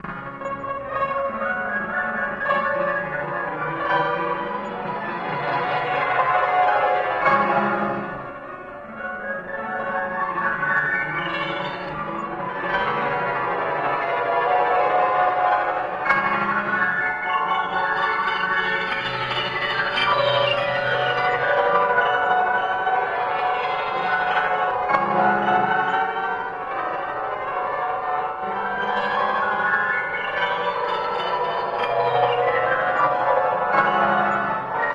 标签： 电子 不和谐 人声 愤怒 ADHD
声道立体声